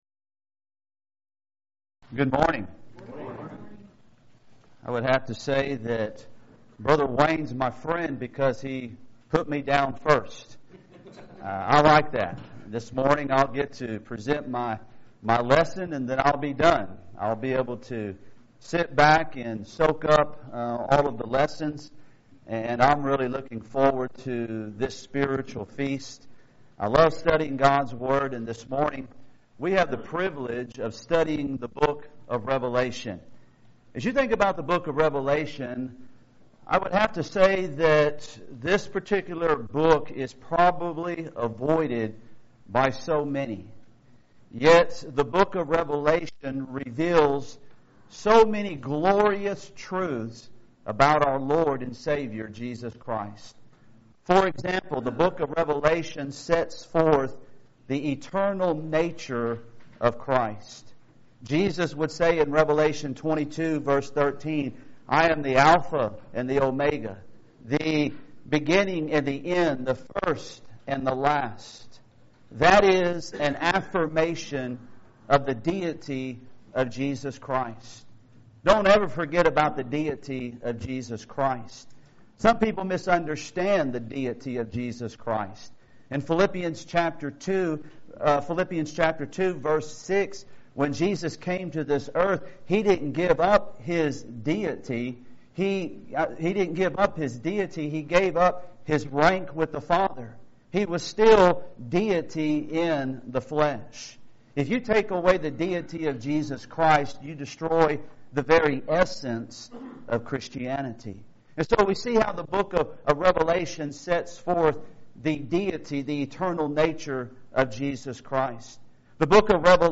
Event: 2019 Focal Point
Preacher's Workshop
lecture